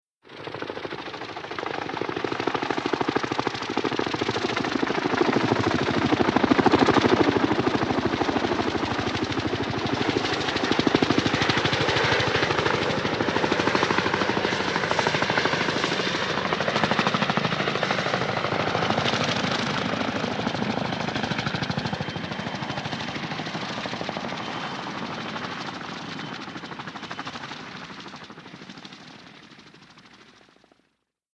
HELICOPTER BELL UH-1 IROQUOIS: EXT: Hover, by, away. Good rotor thwops.